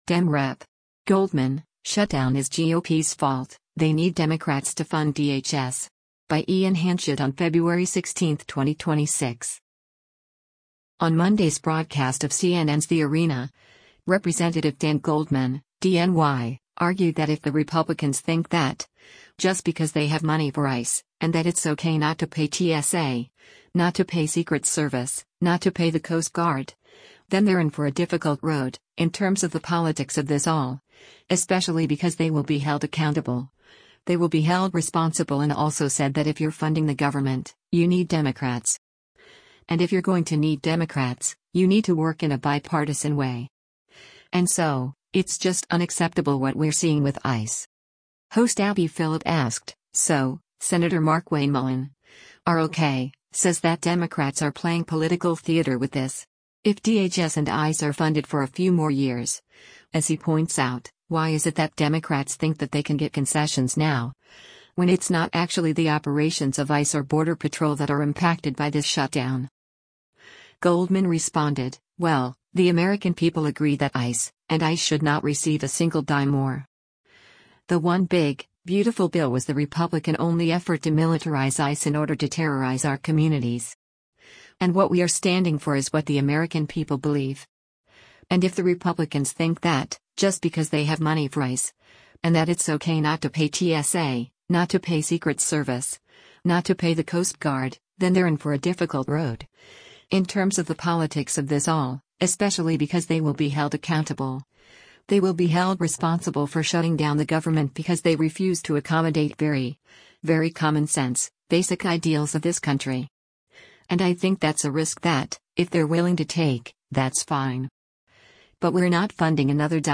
On Monday’s broadcast of CNN’s “The Arena,” Rep. Dan Goldman (D-NY) argued that “if the Republicans think that, just because they have money for ICE, and that it’s okay not to pay TSA, not to pay Secret Service, not to pay the Coast Guard, then they’re in for a difficult road, in terms of the politics of this all, especially because they will be held accountable, they will be held responsible” and also said that “if you’re funding the government, you need Democrats. And if you’re going to need Democrats, you need to work in a bipartisan way. And so, it’s just unacceptable what we’re seeing with ICE.”